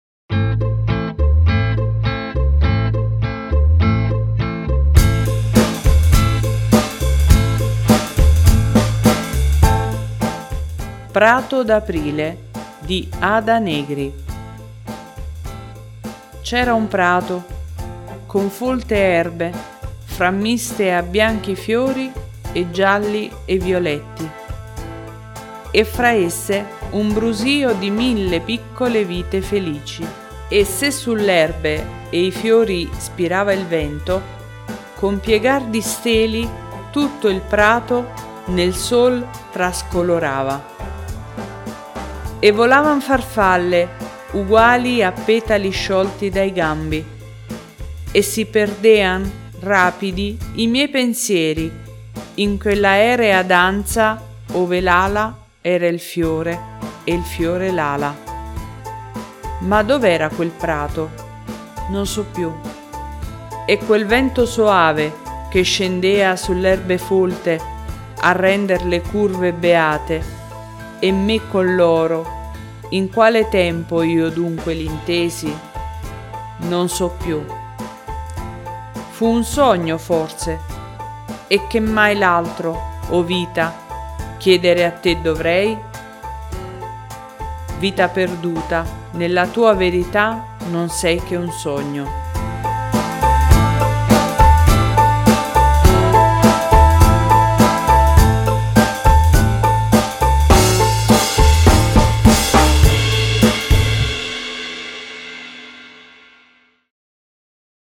Questa sera ho il piacere di leggervi questa bellissima poesia, di Ada Negri.